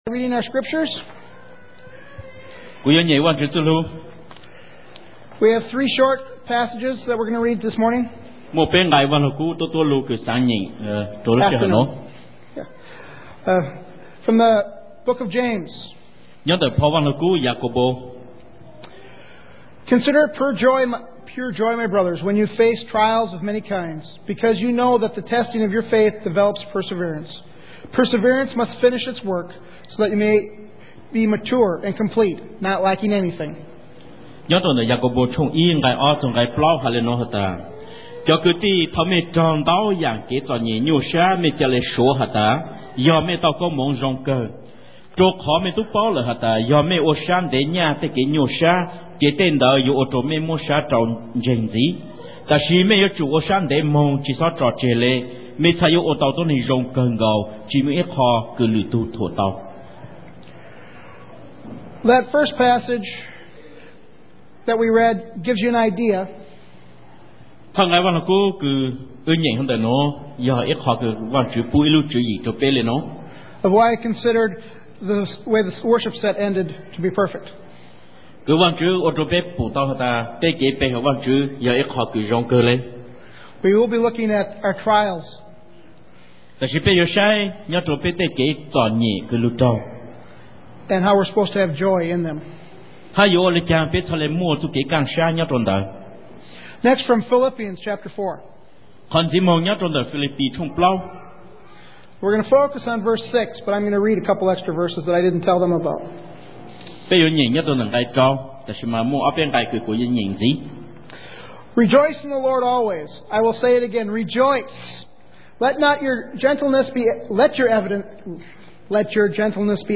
Joint Thanksgiving Service